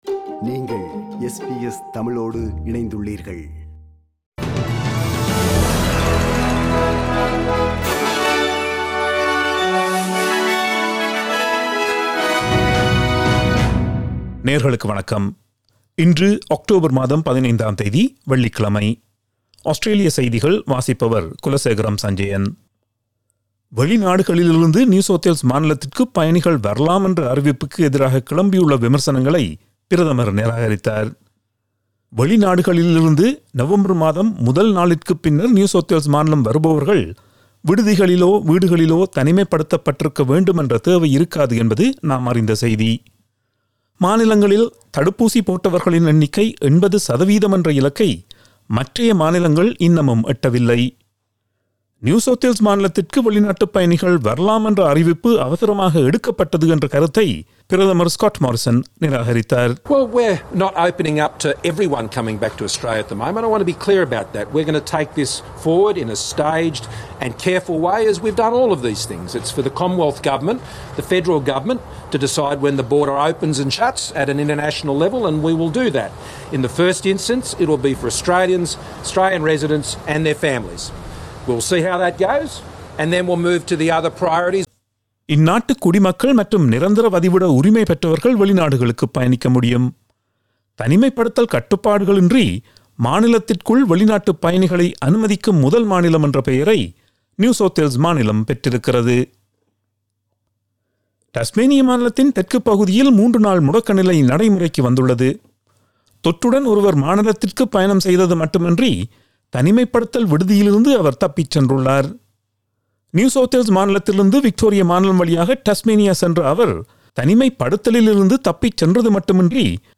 Australian news bulletin for Friday 15 October 2021.